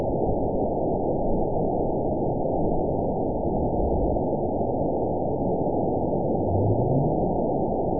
event 920443 date 03/25/24 time 23:27:39 GMT (1 year, 1 month ago) score 9.55 location TSS-AB02 detected by nrw target species NRW annotations +NRW Spectrogram: Frequency (kHz) vs. Time (s) audio not available .wav